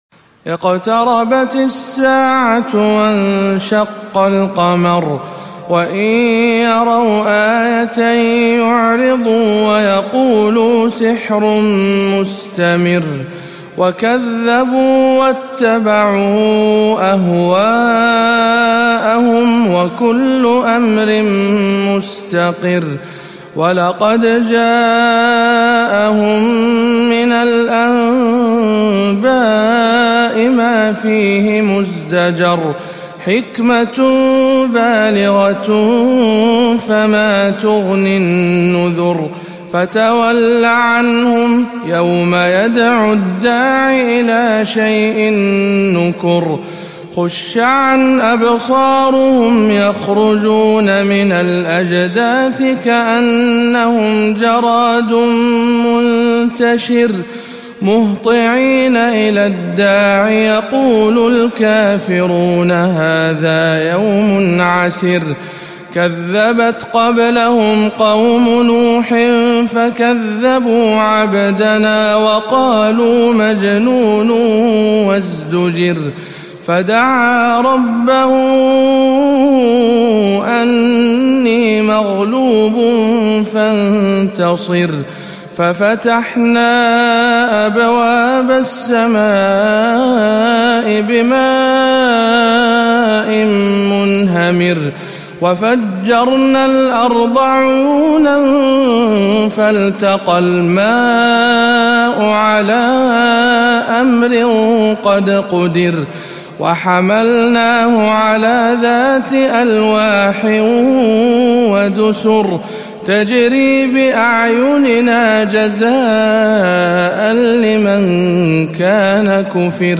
18. سورة القمر بمسجد الحسين بن علي بخليص - رمضان 1437 هـ